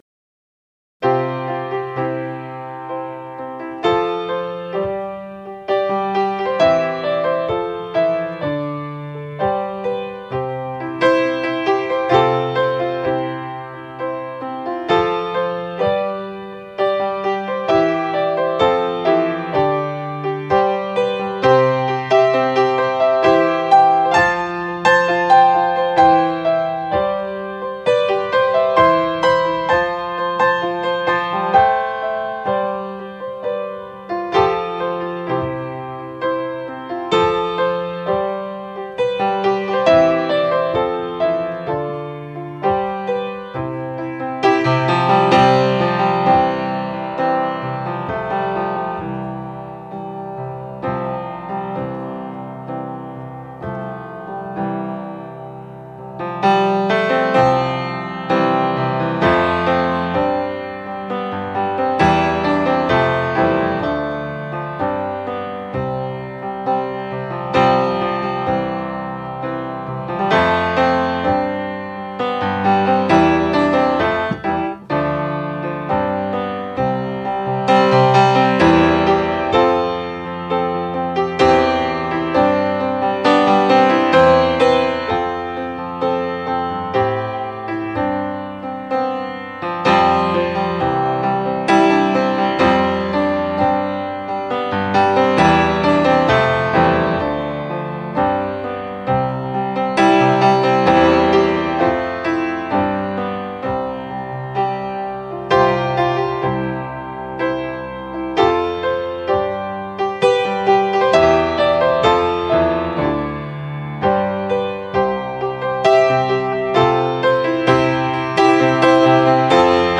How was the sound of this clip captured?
I had to minimize the files, so unfortunately the quality is not the best. Also, most of these were recorded on the first take so you may hear mistakes here or there (i.e. copyist errors…).